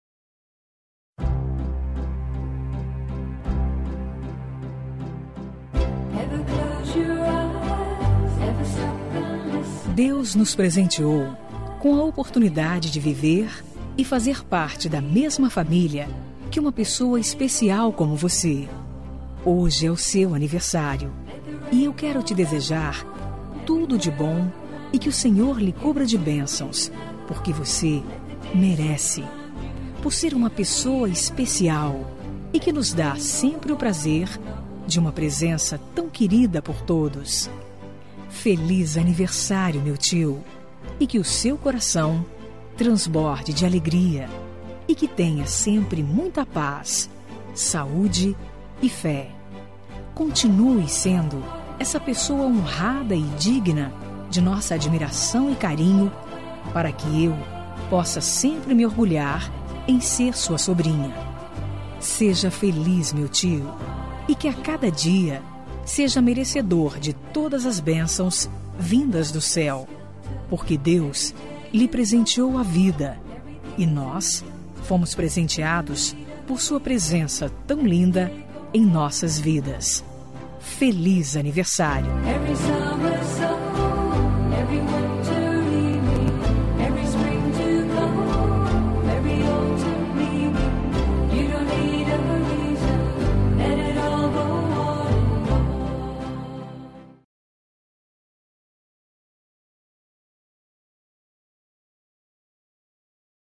Aniversário de Tio – Voz Feminina – Cód: 926